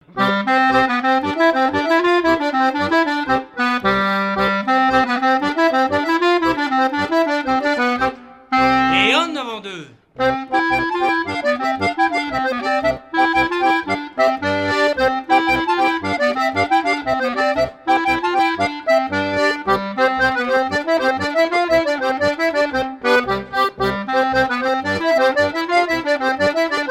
Chants brefs - A danser
danse : branle : avant-deux ;
Pièce musicale éditée